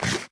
Sound / Minecraft / random / eat2